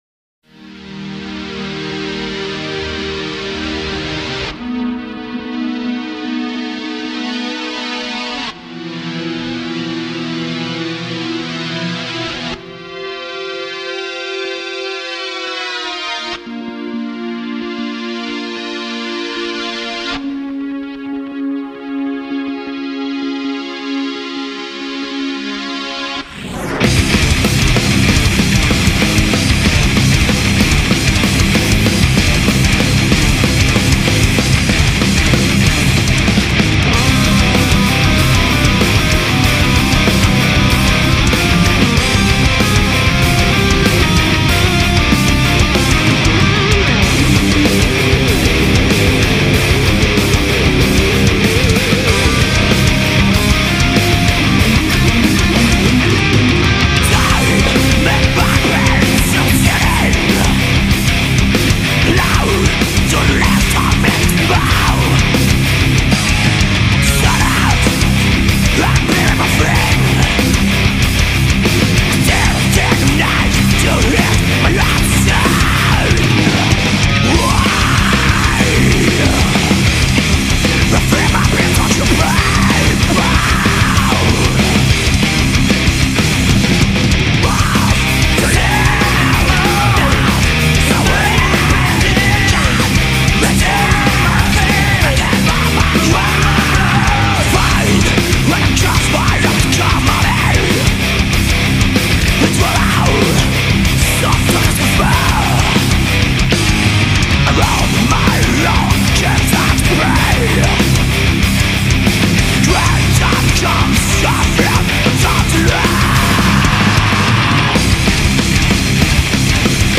[Melodic Trash/Death]